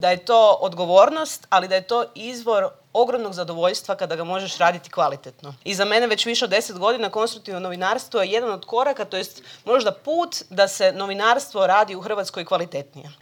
O svemu tome razgovaralo na konferenciji koja je okupila novinare, urednike, medijske stručnjake, ali i studente.
Voditeljica predstavništva Europske komisije u RH Zrinka Ujević: